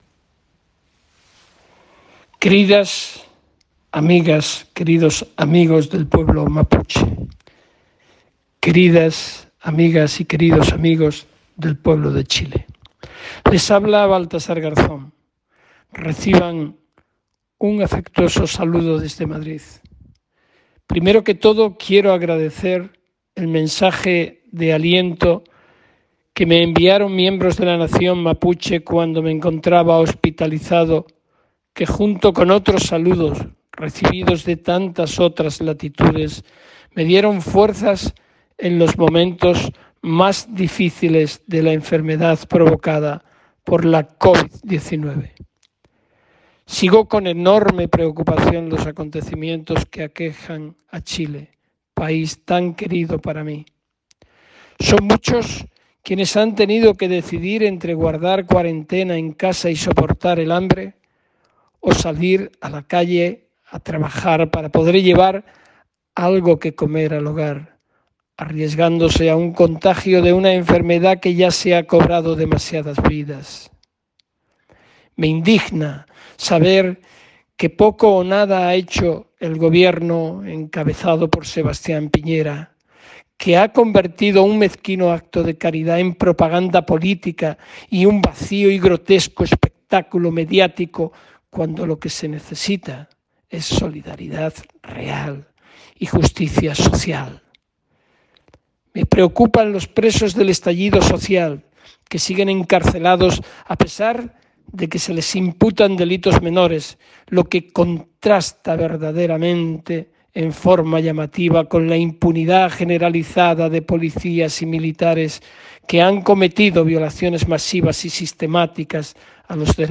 En un audio difundido esta semana el exjuez Baltasar Garzón, que estuvo hace pocos meses en Chile y visitó la Plaza Dignidad en medio de las protestas, envía un conmovedor mensaje a los chilenos y al pueblo mapuche.